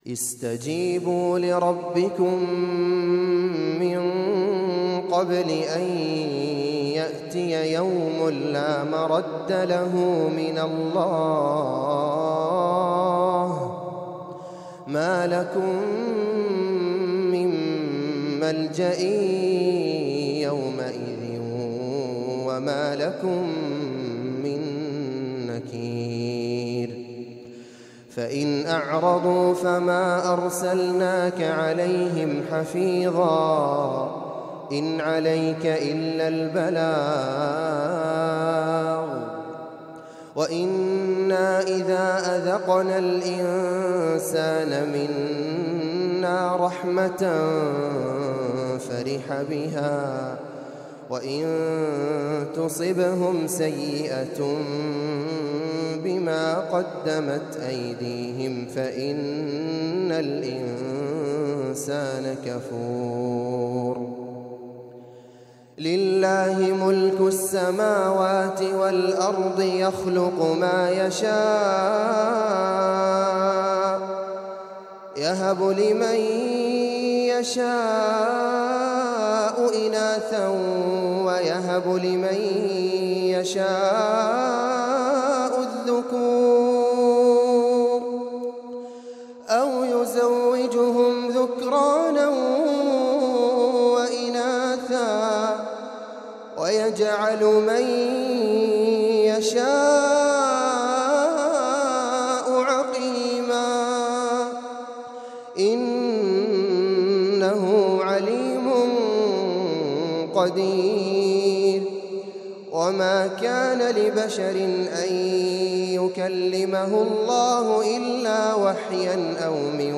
تلاوة خاشعة من أرض اليمن
تلاوة من سورة الشورى للقارئ
مسجد العادل ، عدن ، اليمن